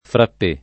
frappé
vai all'elenco alfabetico delle voci ingrandisci il carattere 100% rimpicciolisci il carattere stampa invia tramite posta elettronica codividi su Facebook frappé [fr. frap % ] agg. e s. m.; pl. m. frappés [id.] — come s. m., adatt. in frappè [ frapp $+ ] o frappé [ frapp %+ ], inv.